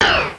Misslock.wav